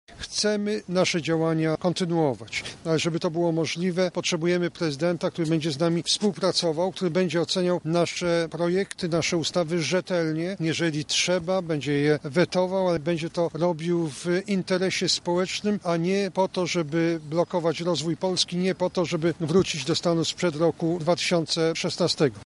W swoim przemówieniu Jarosław Gowin- wicepremier i prezes partii Porozumienie podkreślił fakt szybkiego rozwoju gospodarczego Polski w ostatnich latach oraz bezpieczeństwa zarówno wewnętrznego jak i międzynarodowego.
–mówił wicepremier Jarosław Gowin